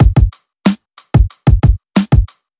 FK092BEAT3-R.wav